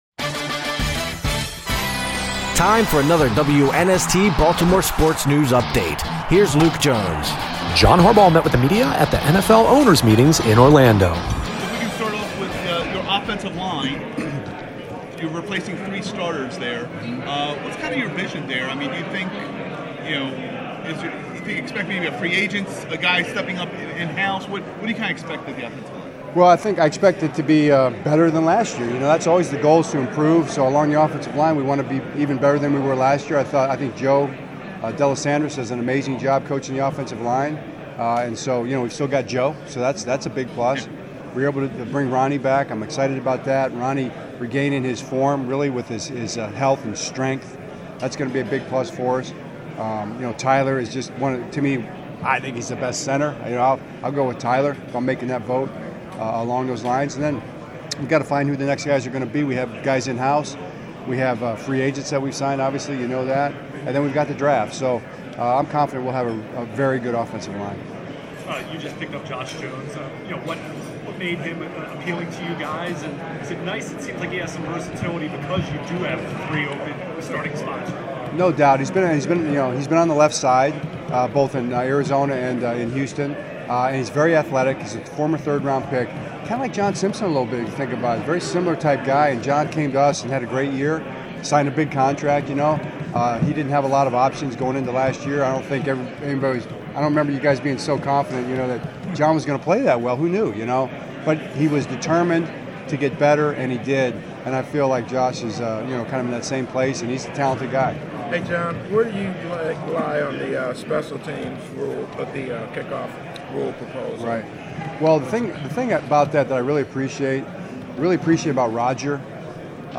John Harbaugh talks state of Ravens at NFL owners meetings in Orlando
Locker Room Sound